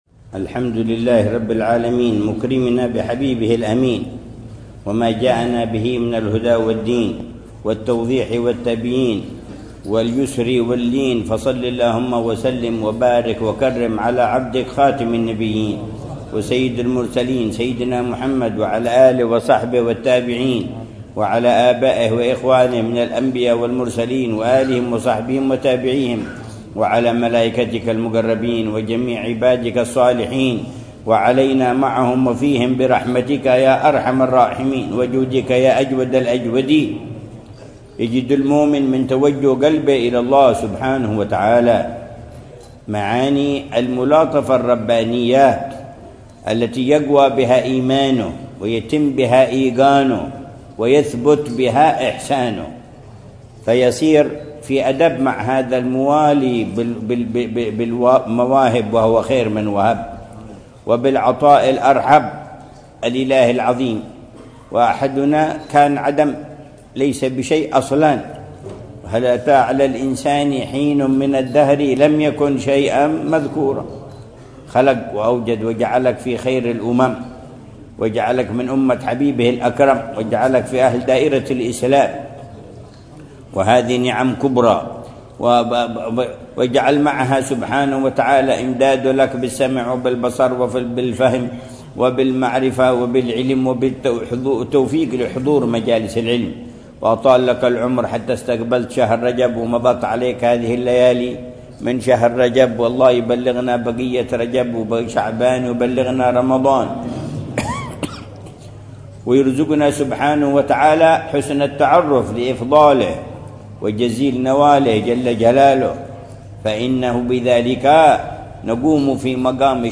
مذاكرة العلامة الحبيب عمر بن محمد بن حفيظ في جامع السعيد، بحارة السعيد، بمدينة تريم، ليلة الثلاثاء 7 رجب الأصب 1446هـ بعنوان: